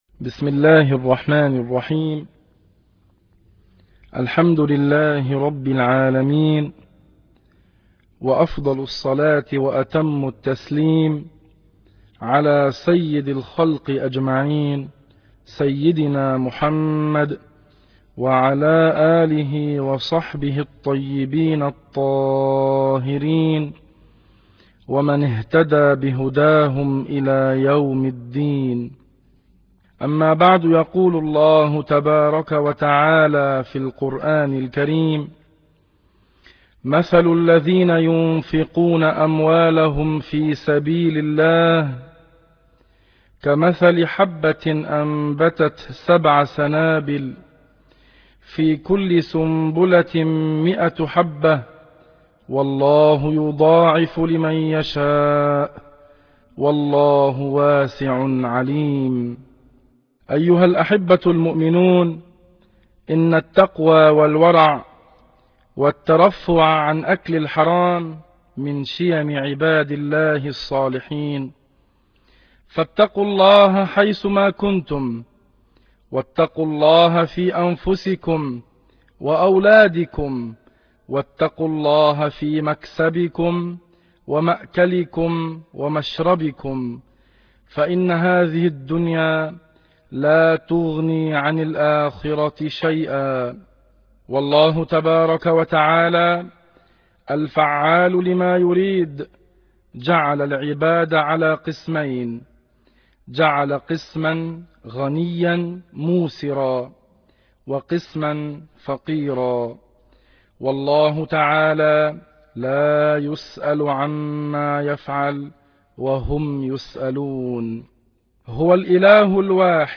درس عن الزهد - موقع أهل السنة و الجماعة
موقع يحتوي على محاضرات دينية هامة لمشايخ جمعية المشاريع الخيرية الاسلامية.